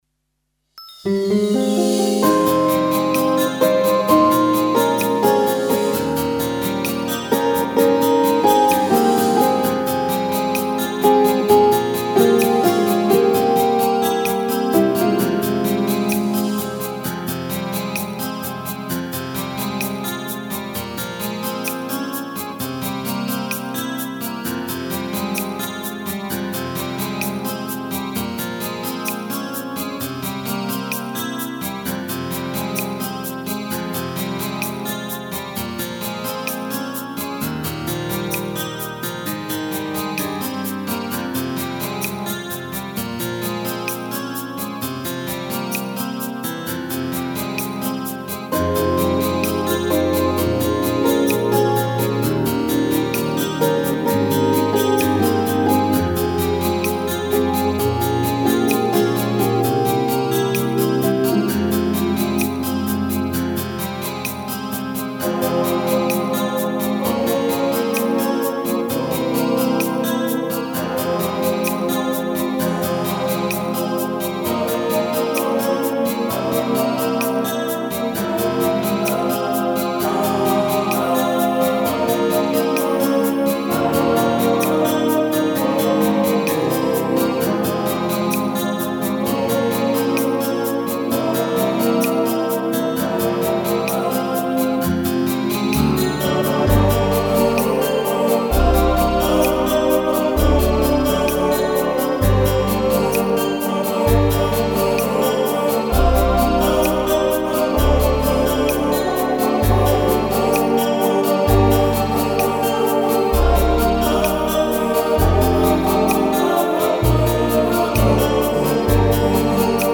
Детская песня
Христианские Песни
караоке
минусовка